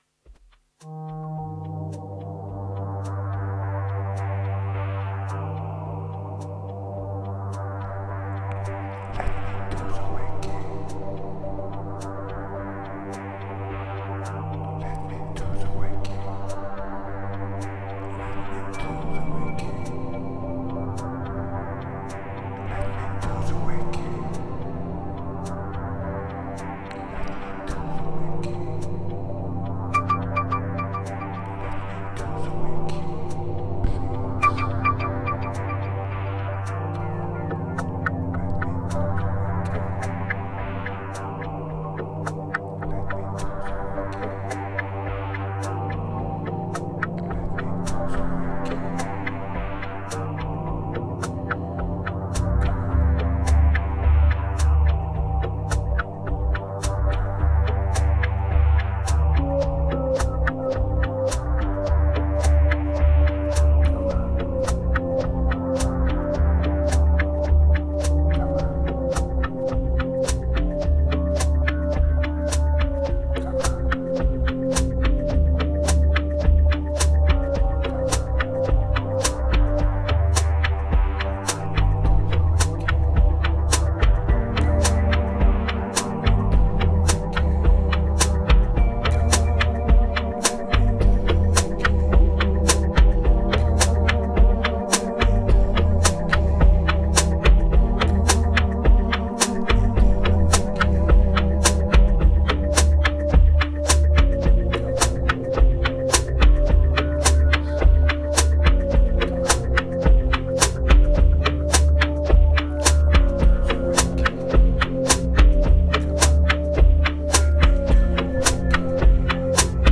3178📈 - 15%🤔 - 107BPM🔊 - 2016-04-07📅 - -337🌟